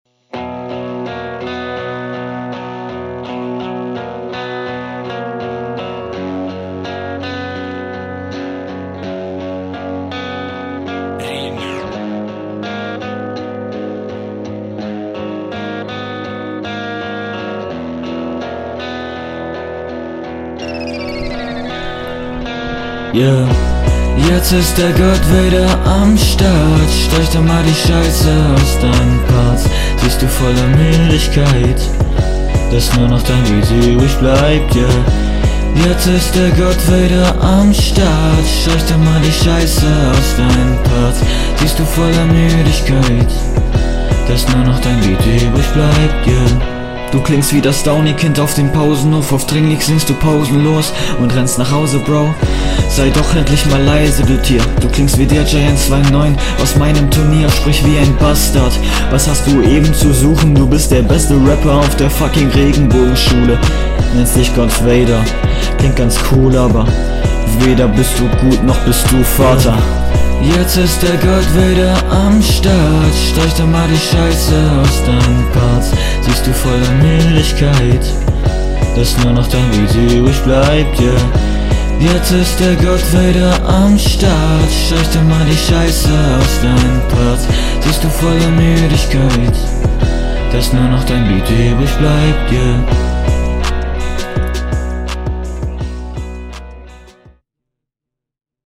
Beat muss was lauter.